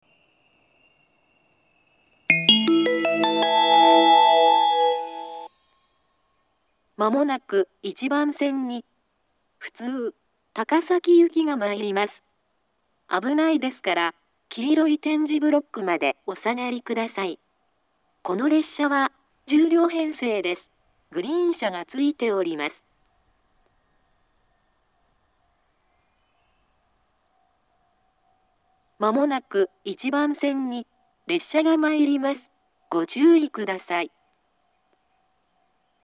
２０１２年頃には放送装置が更新され、自動放送鳴動中にノイズが被るようになっています。
１番線接近放送